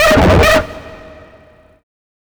OLDRAVE 8 -L.wav